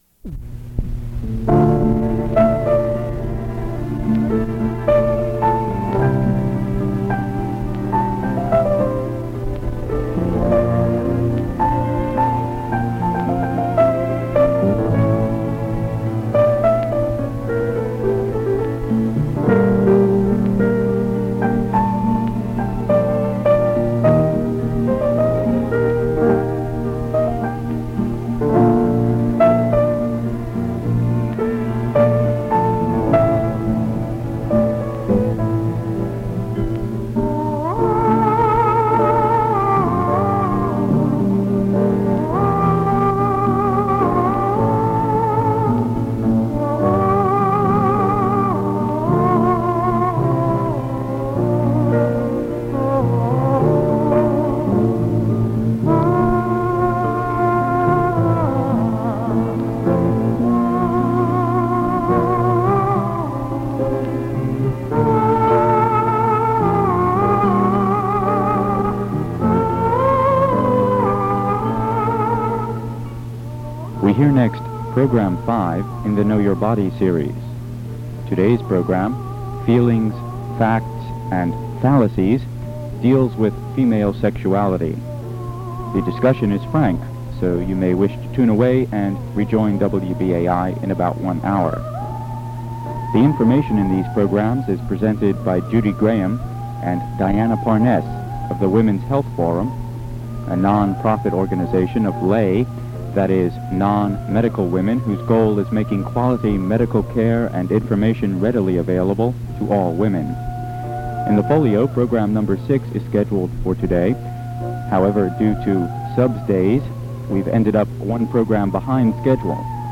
Download File Download File Title Know Your Body: Sexuality Subject Sexuality Body Description From a series of 8 lectures which comprised a course by and for women also entitled "Know Your Body."